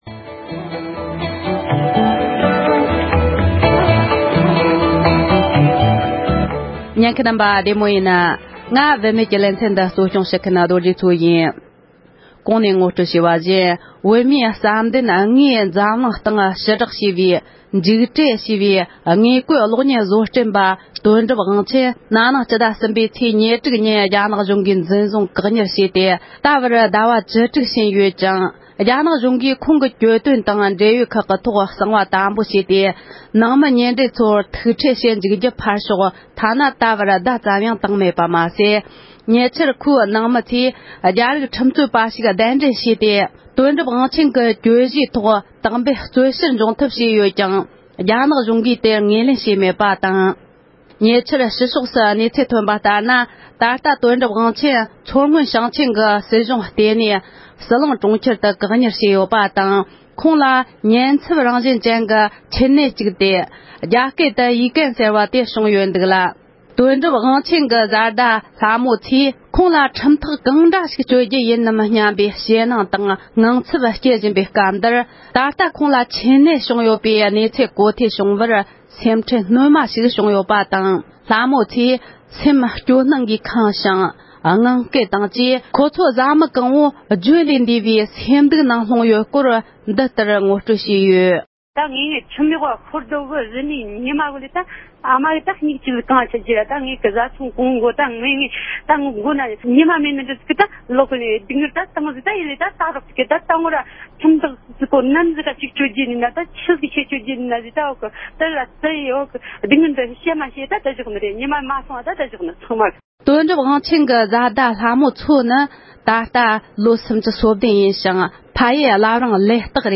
འཇིགས་བྲལ་ཞེས་པའི་བོད་པའི་གློག་བརྙན་བཟོ་མཁན་དོན་གྲུབ་དབང་ཆེན་གྱི་བཟའ་ཟླའི་གནས་སྟངས་ཐད་གླེང་མོལ།